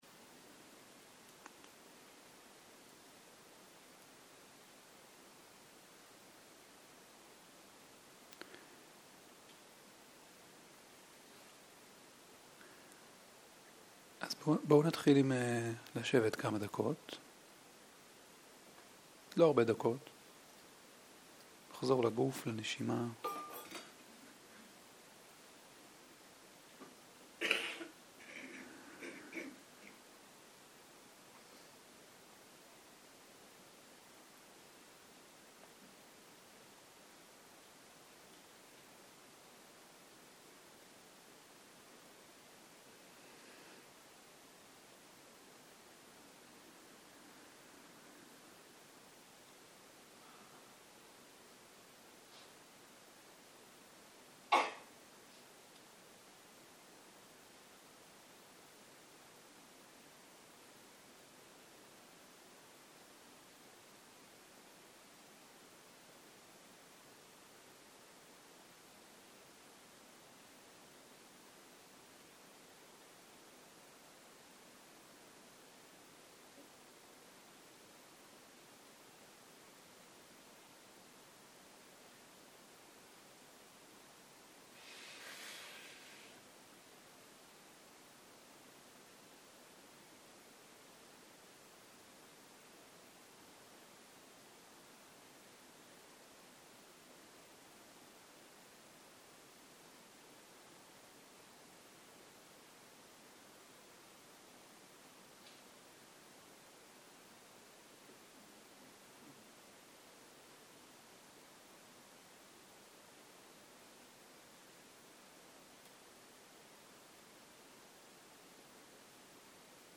ערב - שיחת דהרמה - איך לפגוש את אי הנחת - הקלטה 4